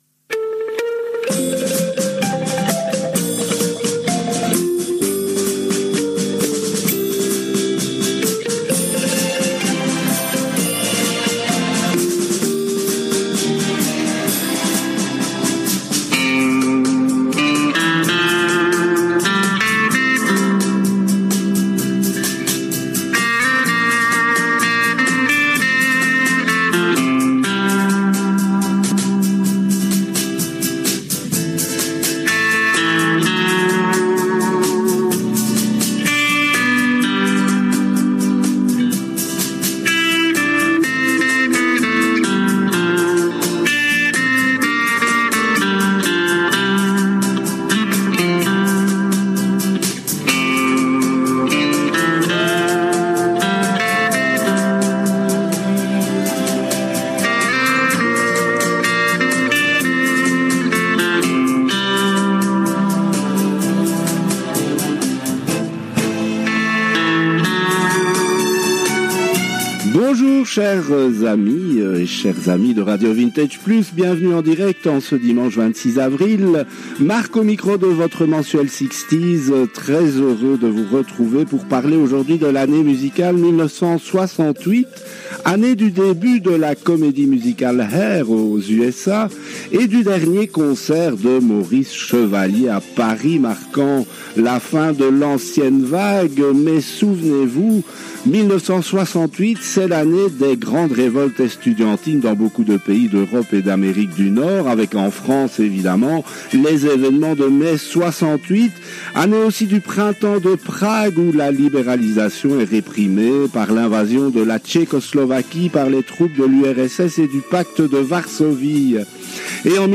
Cette 54ème édition a été diffusée le dimanche 26 avril 2026 à 10 heures en direct des studios de RV+ en BELGIQUE
Tous les titres diffusés ont été classés parmi les 10 meilleures ventes en 1968 car les hit-parades étaient très parcellaires à l’époque. Une multitude de styles, des anniversaires, des archives et anecdotes sur l’histoire des chansons ou des artistes, la rubrique « EUROVISION » et la rubrique avec une reprise récente d’un tube de 1968.